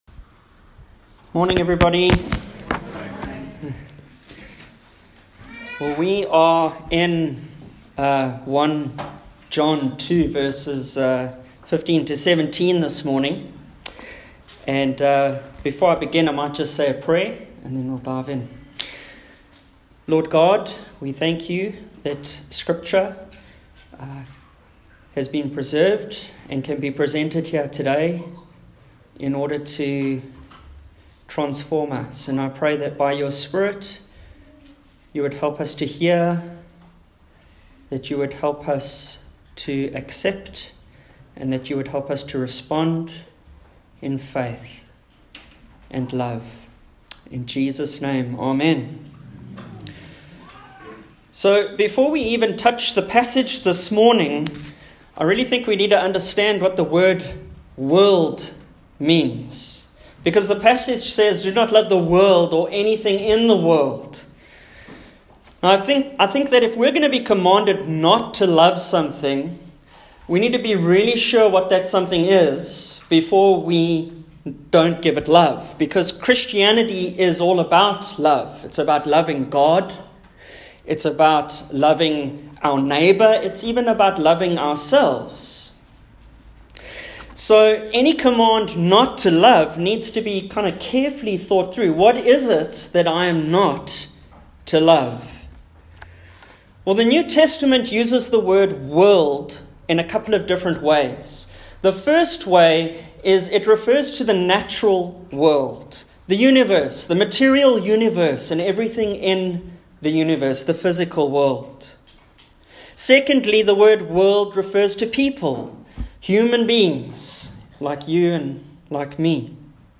Service Type: Sunday AM Topics: desires , lusts , Pride , worldliness « 1 John 2